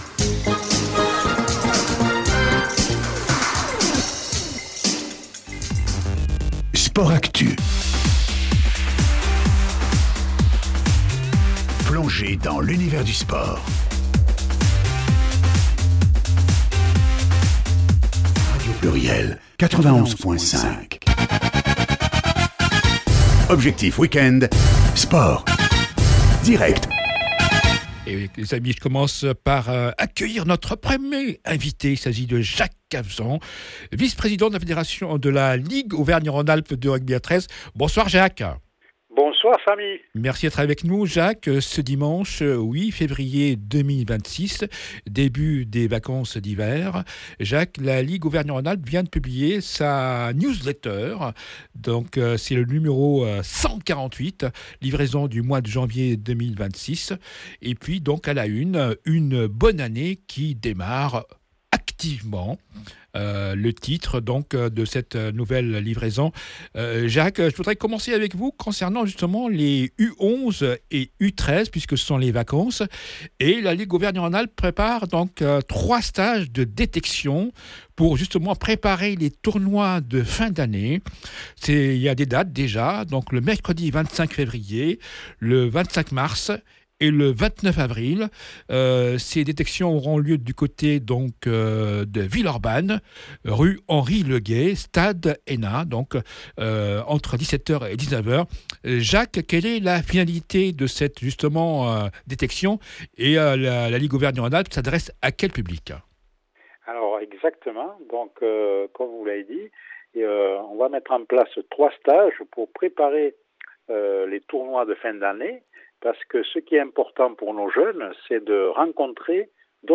L’interview du président mois de DECEMBRE 2025